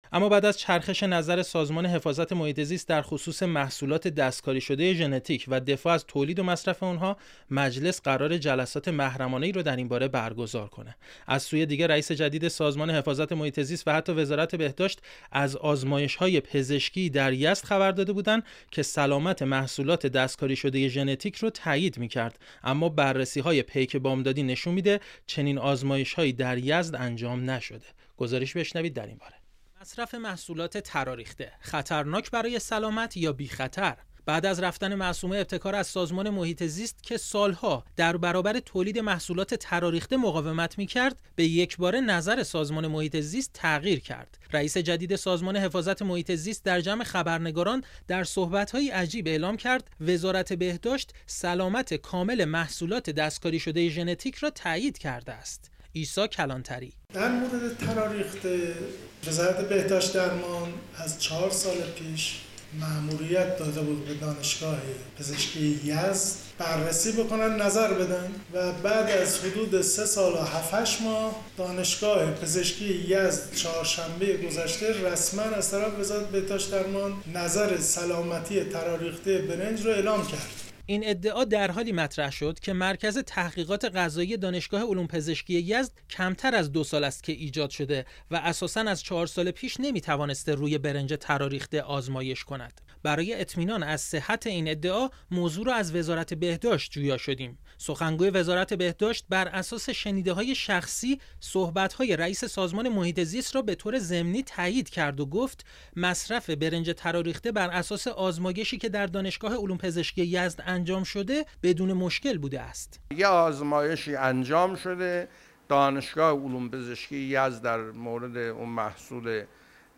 در گزارش صوتی کوتاه زیر به‌صورت کامل این موضوع شرح داده شده است: